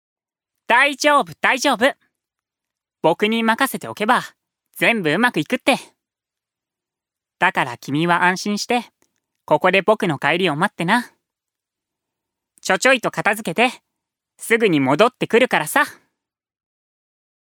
ジュニア：男性
セリフ１